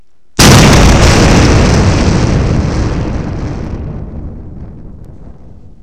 explosion.wav